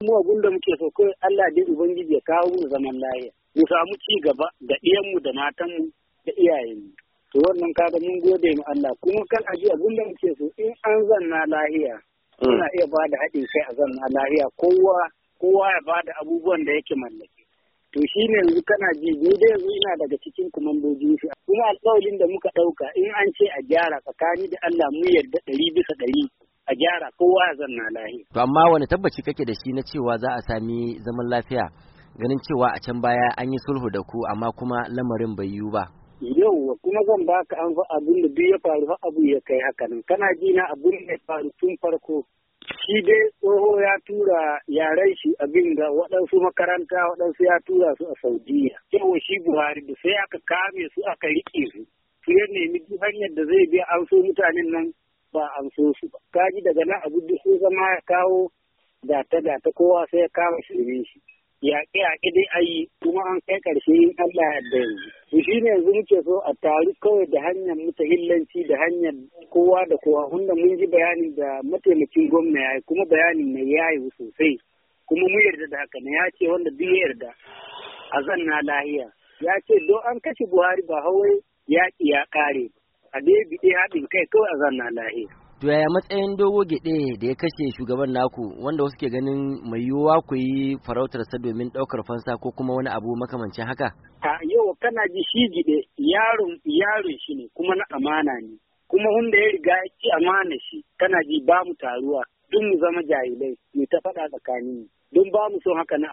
A cikin wata hira da Muryar Amurka